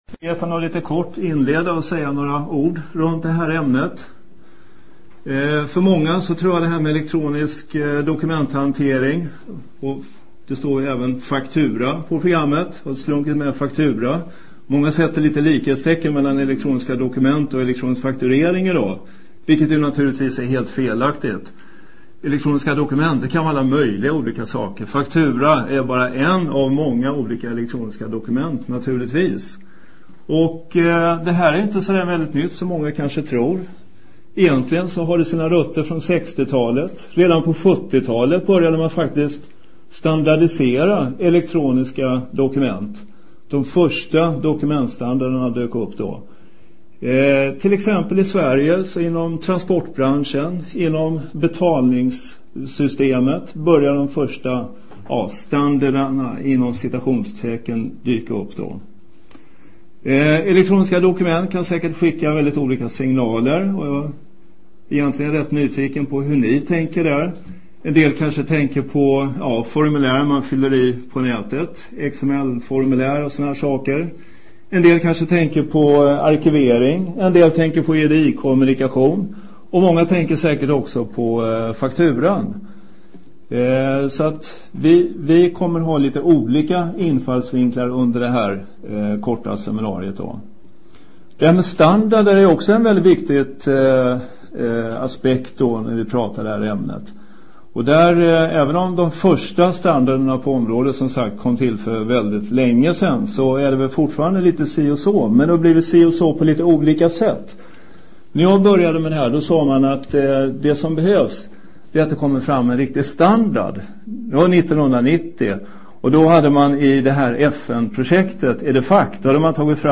Efter flera �rs pratande om effektivisering av dokumentfl�det mellan f�retag s� har det �ntligen blivit en islossning. P� seminariet kommer vi att redovisa allt fr�n en l�sning f�r tusentals sm�f�retag, ge status f�r den offentliga sektorn, visa p� tj�nster hos bankerna samt diskutera utvecklingstrender